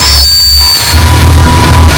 sonarPingWaterVeryCloseShuttle1.ogg